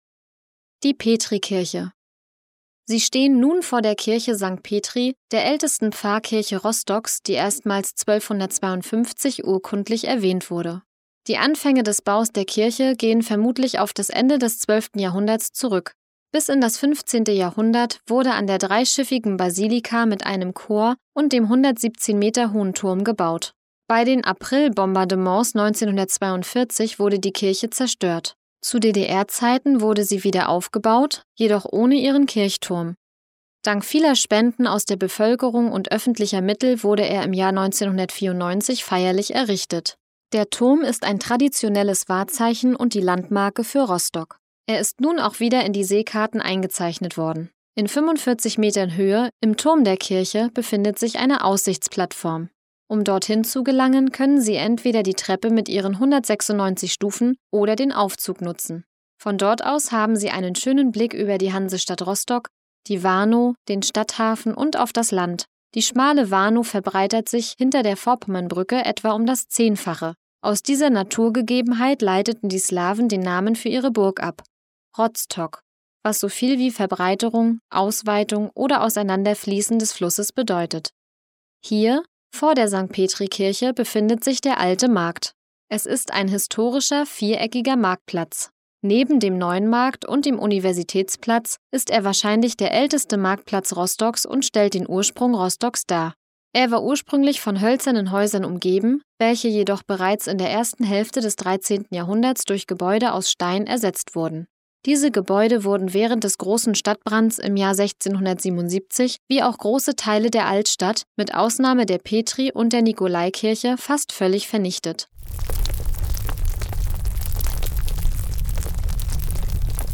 Audioguide Rostock - Station 16: St. Petrikirche